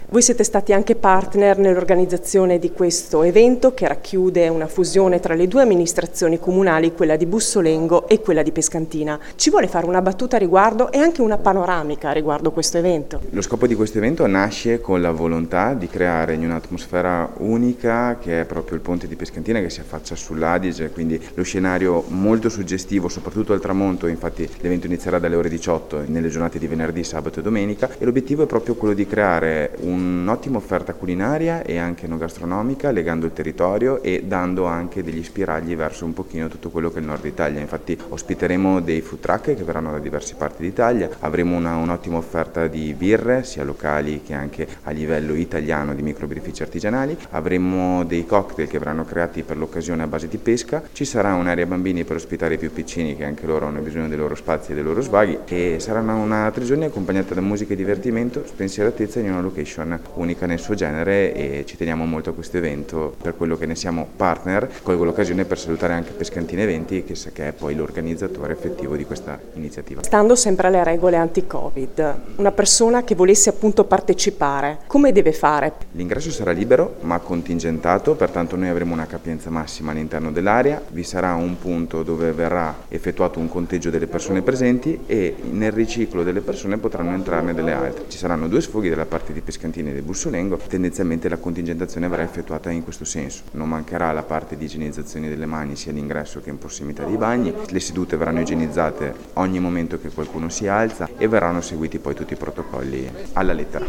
Foto e interviste